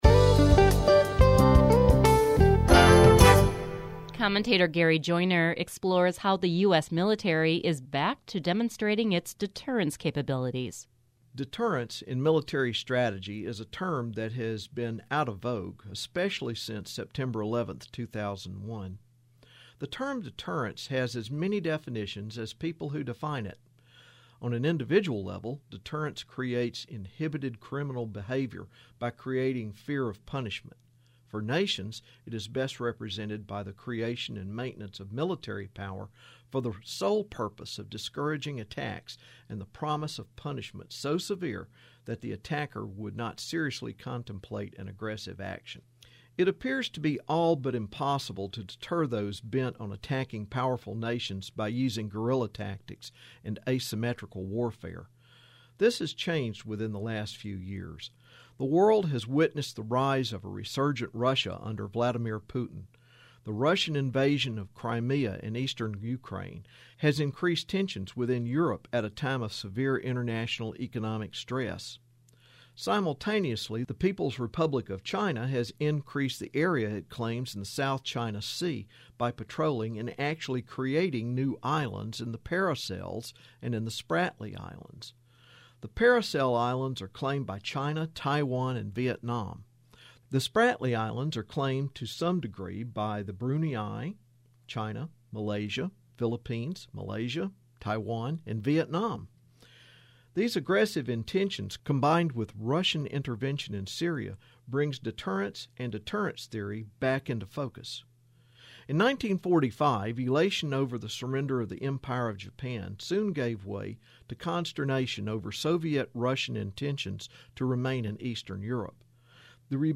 History Matters #452 - Military deterrence commentary